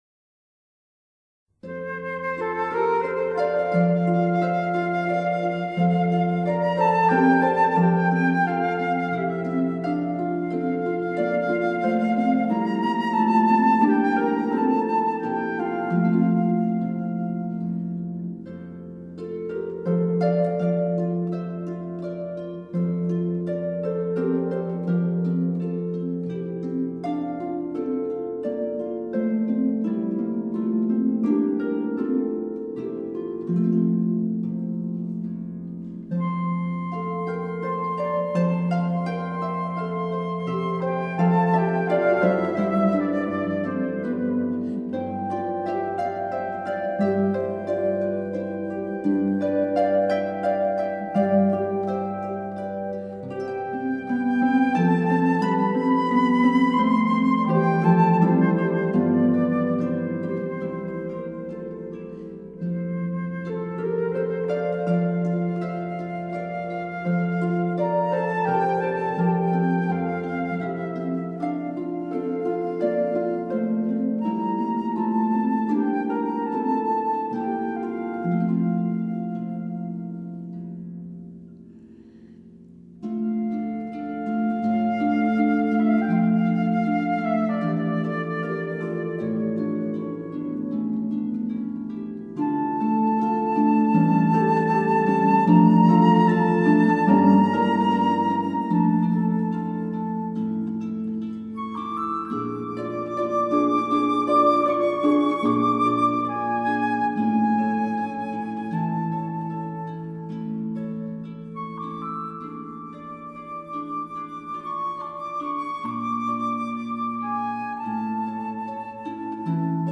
希望这张专辑能够帮助入睡.，歌曲就不多介绍啦，都是一些经典的摇篮曲，希望大家喜欢。